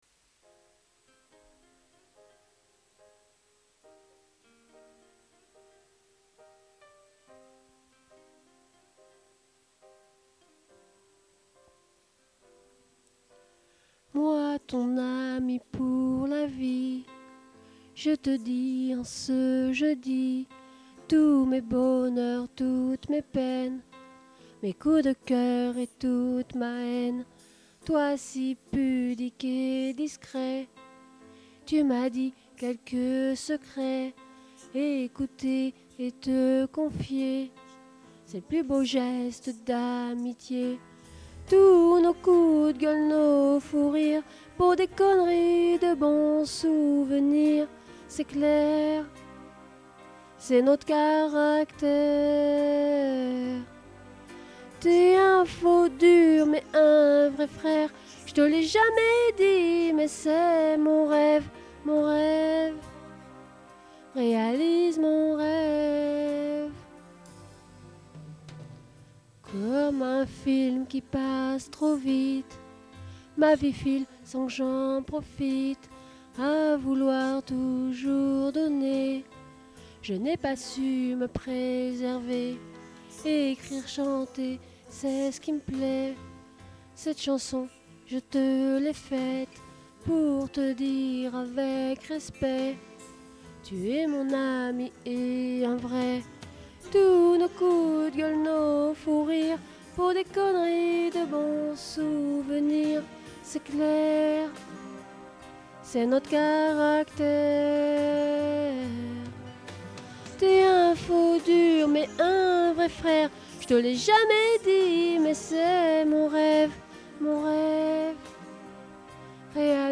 Je chante parce que j'aime ça, il me manque des cours de chant pour faire quelque chose de juste et de professionnel.